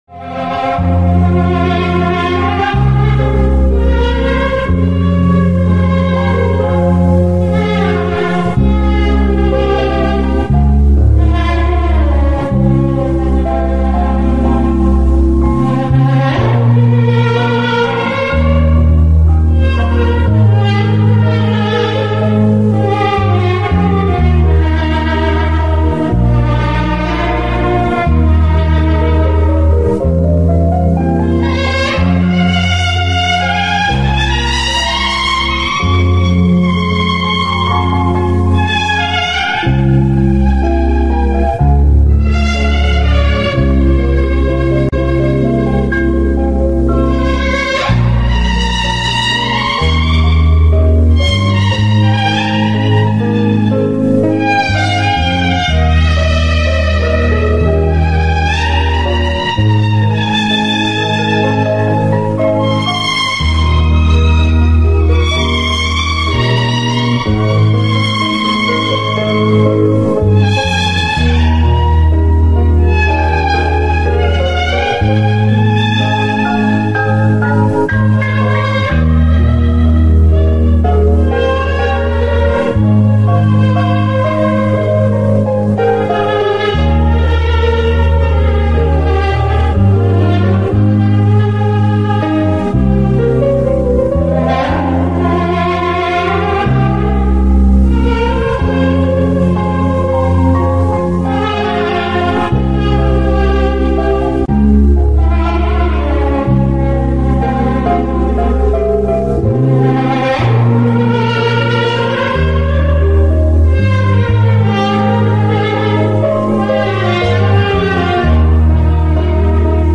Narasumber: Dr. Okky Madasari - Pengamat Sosial Politik